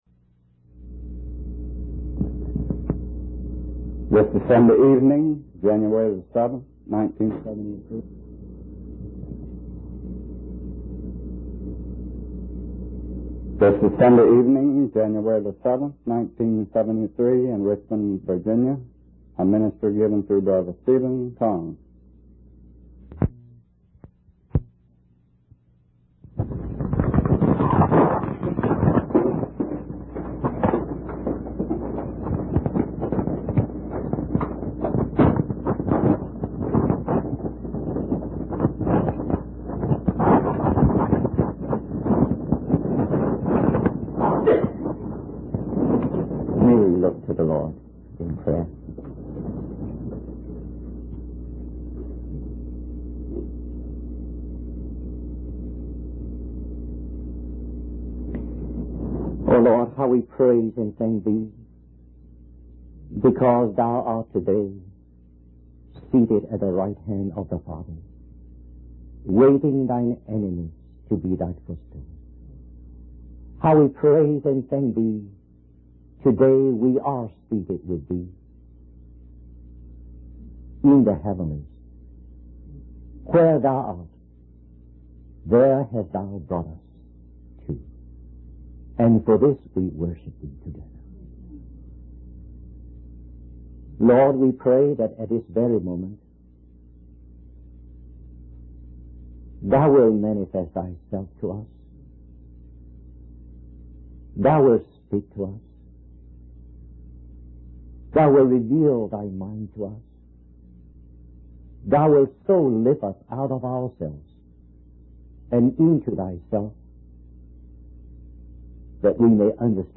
In this sermon, the preacher discusses the concept of Judaism and its four main features.